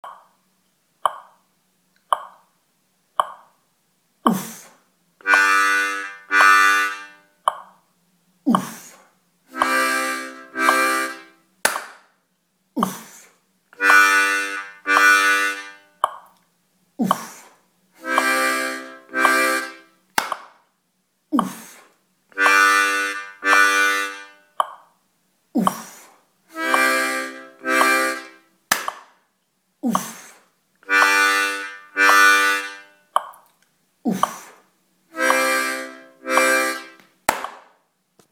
ПРИМЕРЫ РИТМОВ, СЫГРАННЫЕ МЕДЛЕННО:
Все ритмы сыграны на гармошке в тональности «до мажор» под онлайн метроном, со скоростью 56 ударов в минуту.